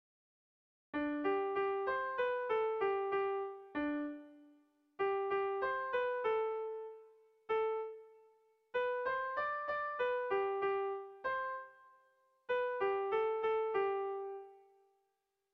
Erromantzea
Lauko txikia (hg) / Bi puntuko txikia (ip)
AB